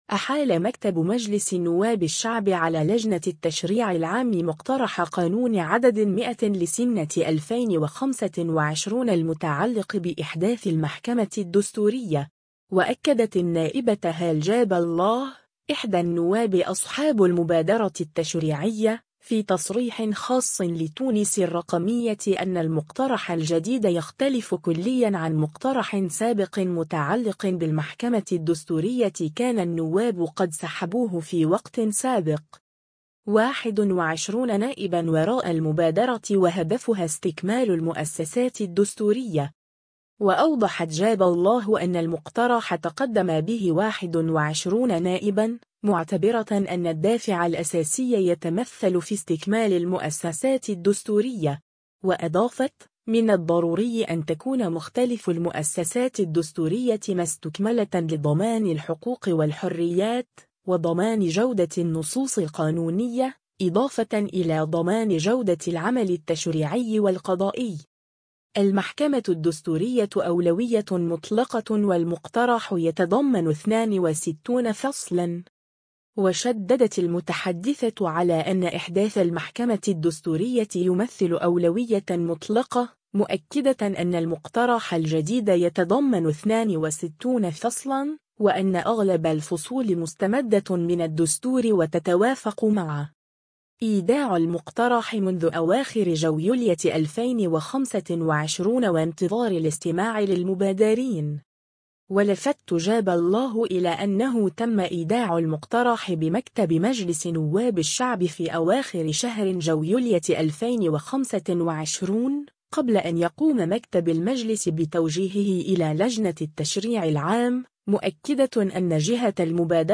وأكدت النائبة هالة جاب الله، إحدى النواب أصحاب المبادرة التشريعية، في تصريح خاص لـ”تونس الرقمية” أن المقترح الجديد يختلف كليًا عن مقترح سابق متعلق بالمحكمة الدستورية كان النواب قد سحبوه في وقت سابق.